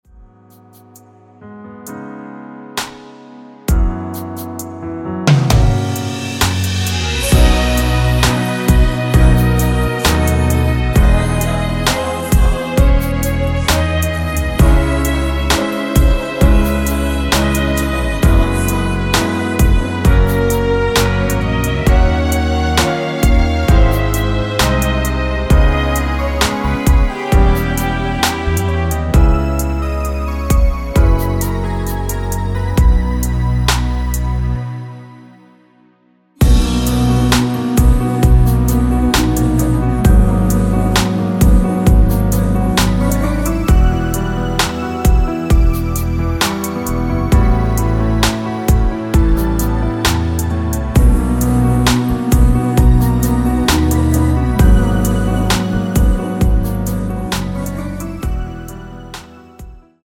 원키 코러스 포함된 MR입니다.
앞부분30초, 뒷부분30초씩 편집해서 올려 드리고 있습니다.
중간에 음이 끈어지고 다시 나오는 이유는